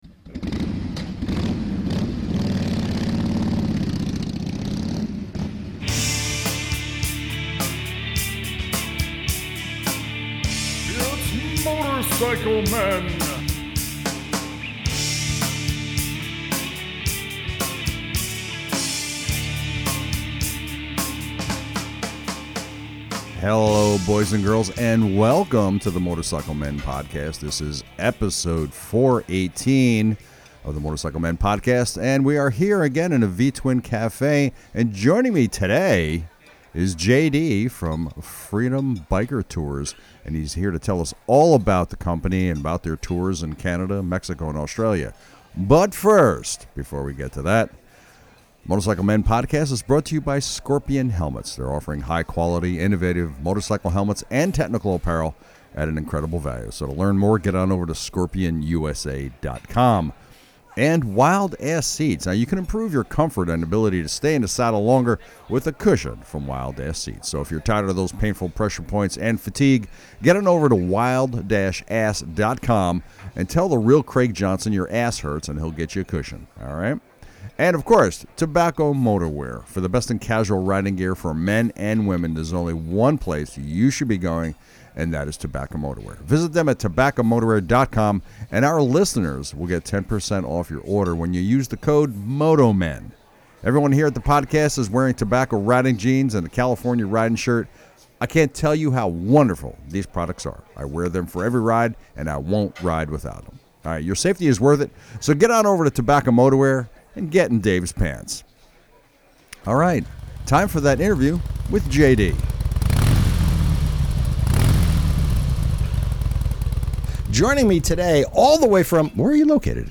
Episode 418 - Interview